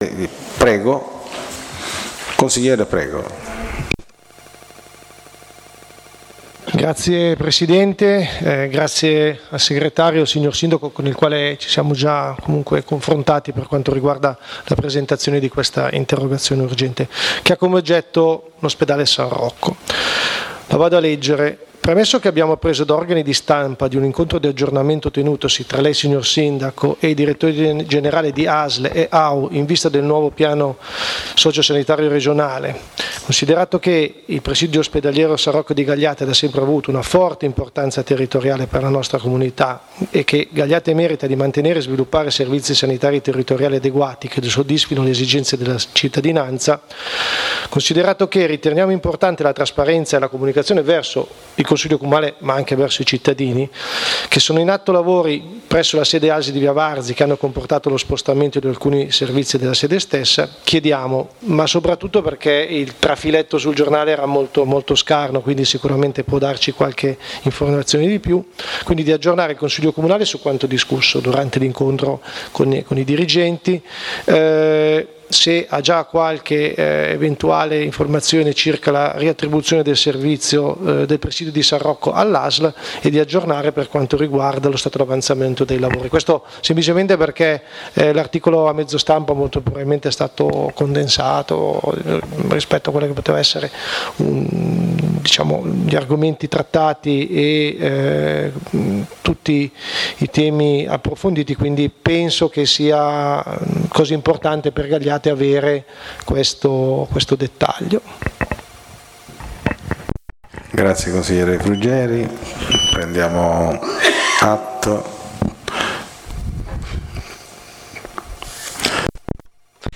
Il giorno 25 settembre 2025 si è riunito il Consiglio Comunale in seduta pubblica presso il Salone Ovest (Neogotico) del Castello Visconteo Sforzesco